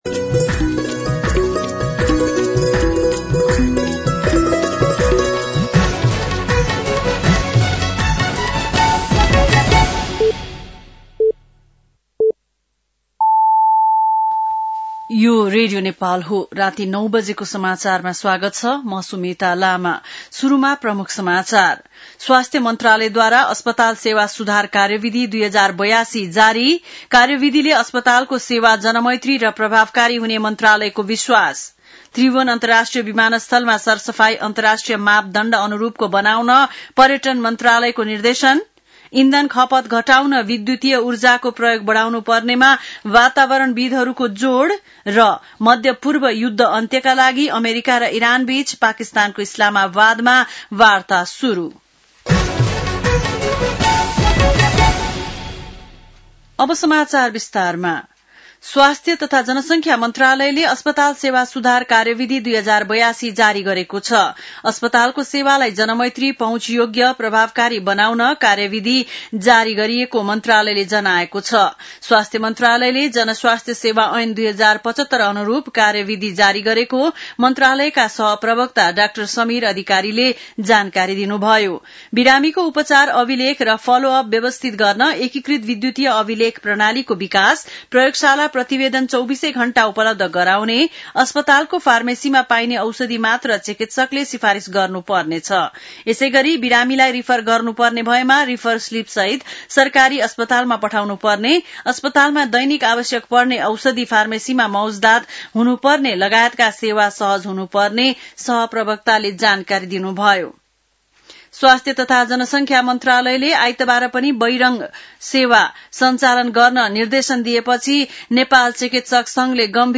बेलुकी ९ बजेको नेपाली समाचार : २८ चैत , २०८२
9-pm-nepali-news-.mp3